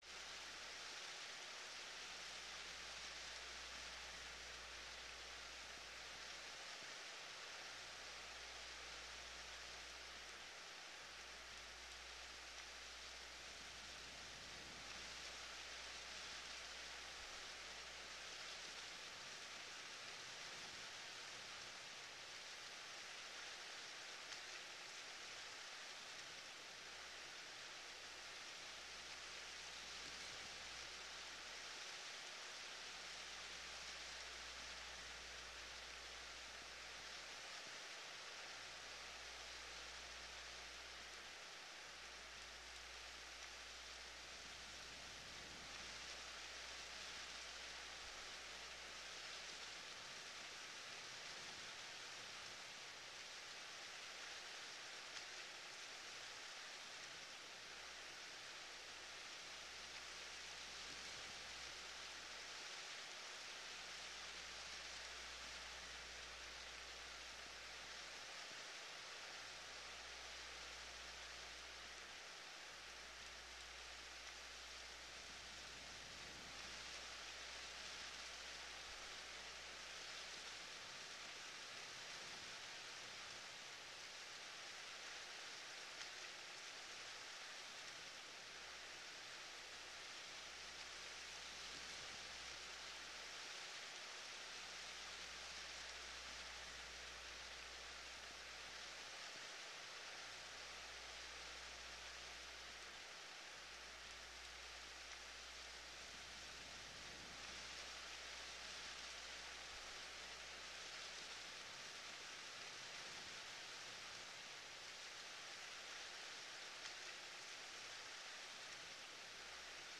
Шум ветра звуки скачать, слушать онлайн ✔в хорошем качестве
Лес с ветром и скрипящими деревьями Скачать звук music_note Ветер , Шум ветра save_as 4.1 Мб schedule 3:03:00 6 2 Теги: mp3 , ветер , звук , Звуки природы , Лес , Листья , Природа , скрип , скрипы , шум ветра